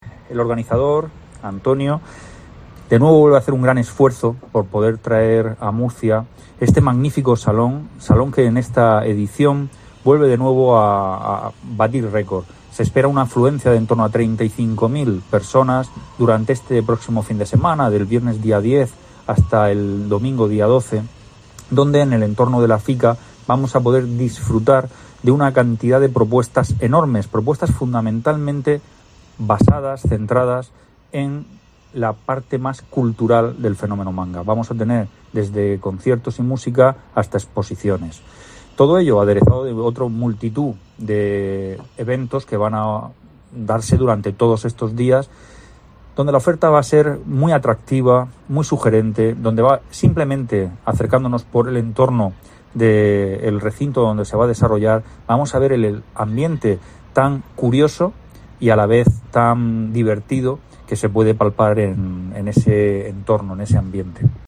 Jesús Pacheco, concejal de Turismo, Comercio y Consumo